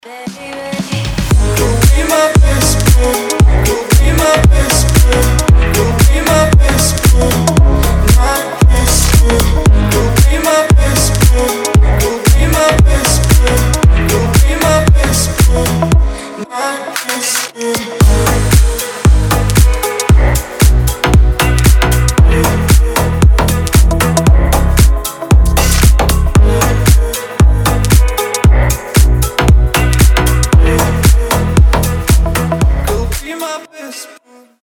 • Качество: 320, Stereo
женский голос
басы
чувственные
nu disco
Стиль: deep house, indie dance